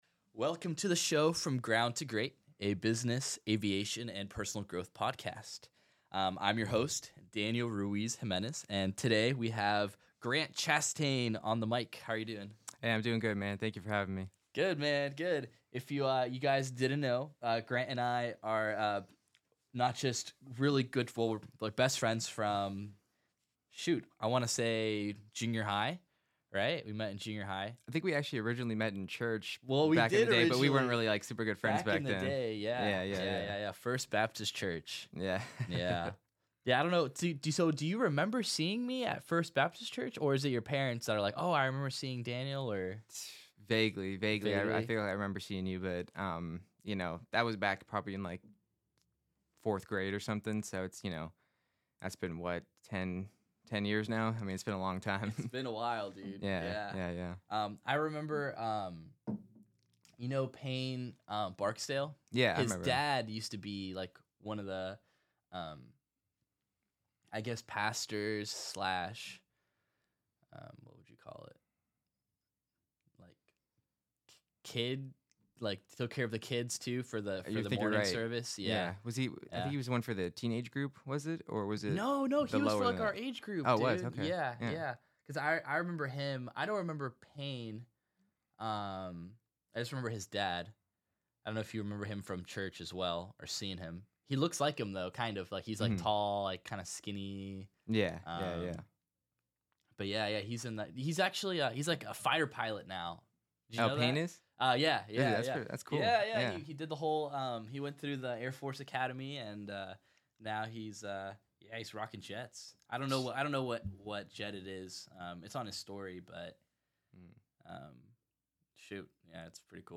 In this episode, lifelong friends and business partners explore the world of aviation, share their entrepreneurial insights, and offer valuable advice on cultivating healthy habits for personal development.